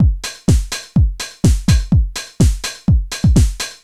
Index of /musicradar/retro-house-samples/Drum Loops
Beat 16 Full (125BPM).wav